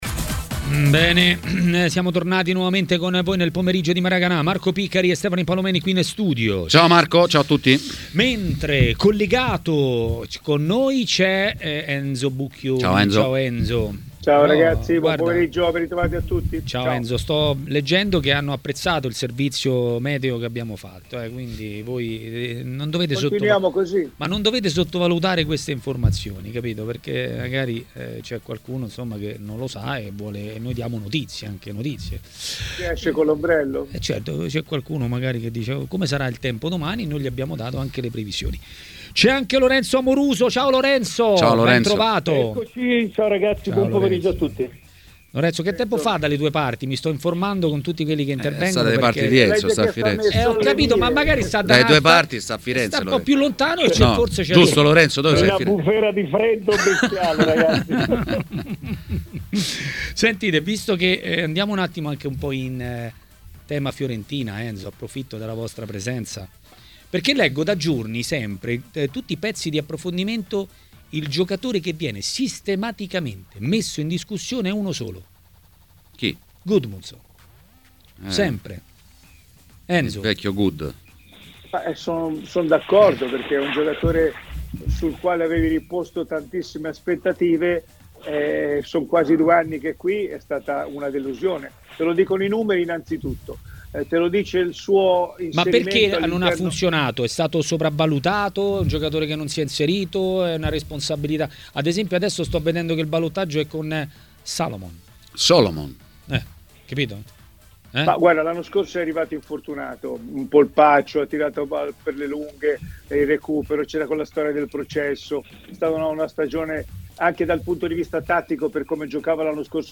Ospite di Maracanà, nel pomeriggio di TMW Radio, è stato l'ex calciatore Lorenzo Amoruso.